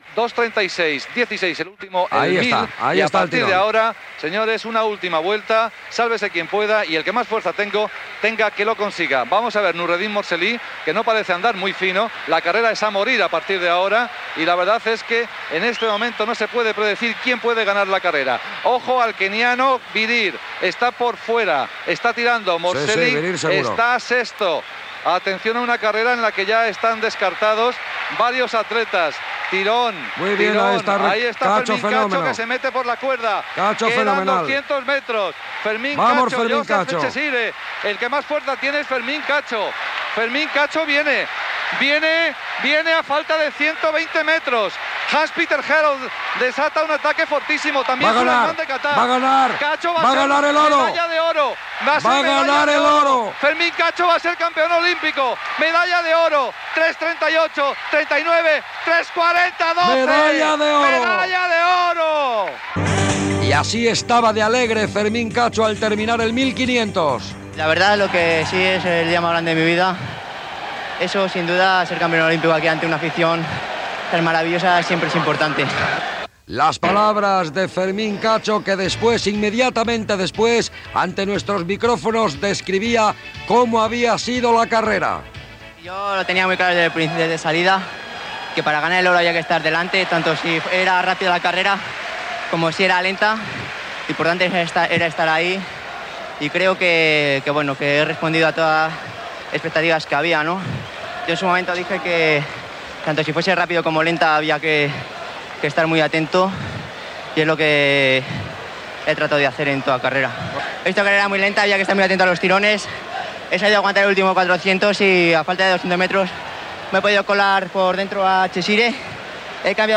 Repetició de la narració del final de la cursa dels 1500 metres als Jocs Olímpics de Barcelona, amb la victòria de Fermín Cacho. Declaracions de l'atleta després de guanyar la medalla d'or
Esportiu